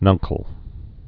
(nŭngkəl)